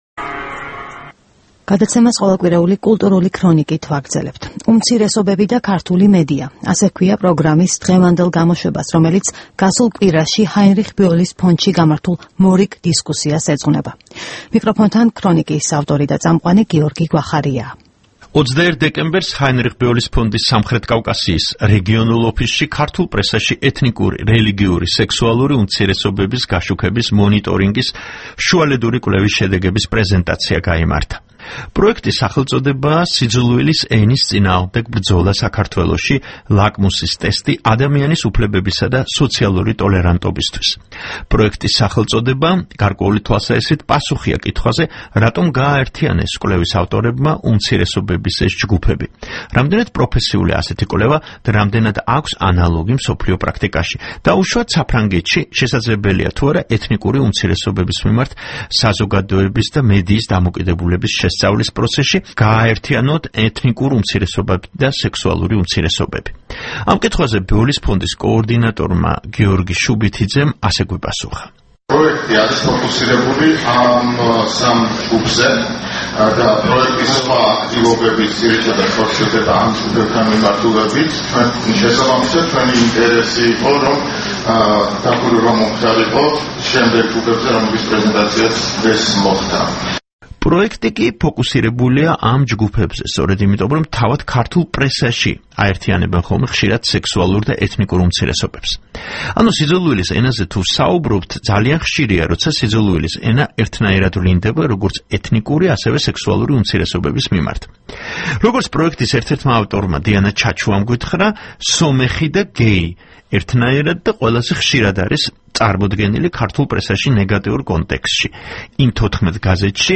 სიუჟეტი